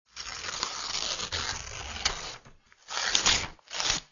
Papier déchiré
papier_dechire.mp3